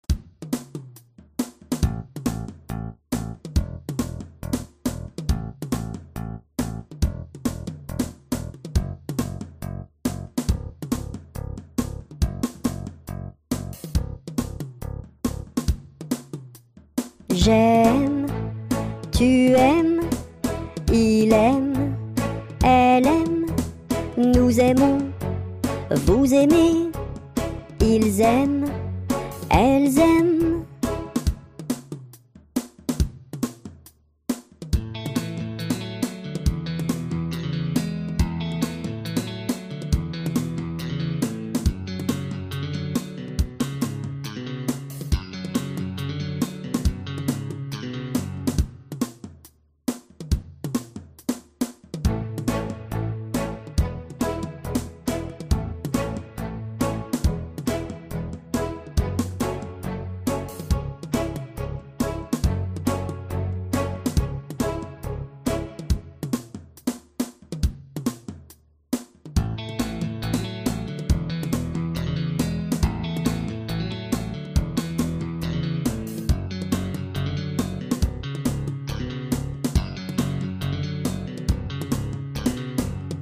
Sing the conjugation # Pop